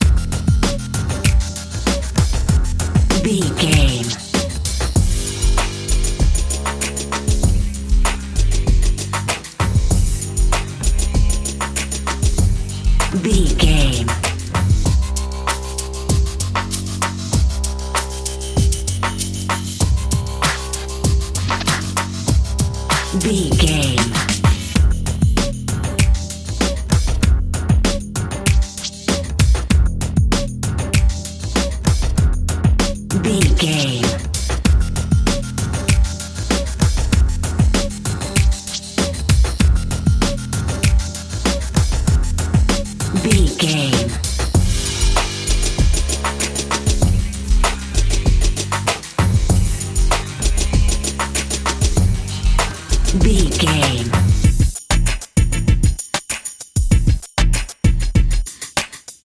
Aeolian/Minor
synthesiser
drum machine
hip hop
Funk
neo soul
acid jazz
energetic
bouncy
funky